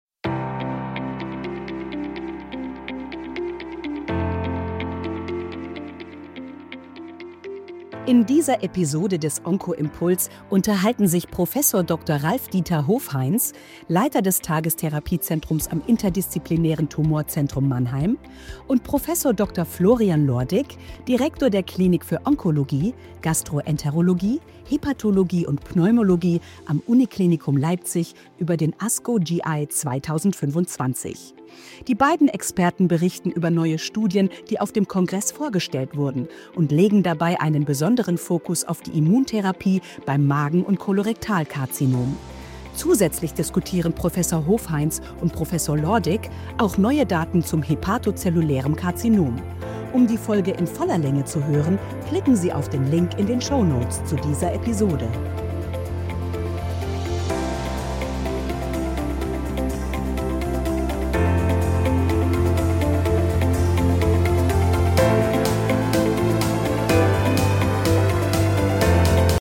Unterhaltung